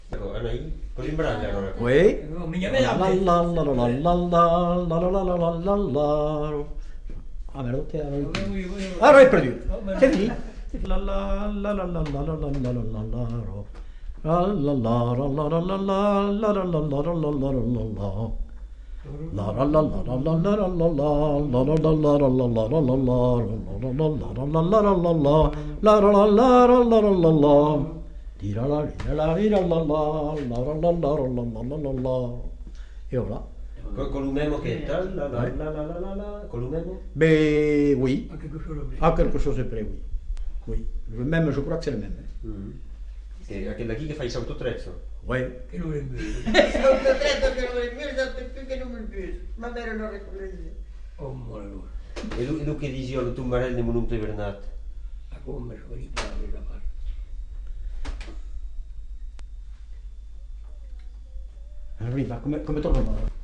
Rondeau (fredonné)
Aire culturelle : Agenais
Genre : chant
Effectif : 1
Type de voix : voix d'homme
Production du son : fredonné
Danse : rondeau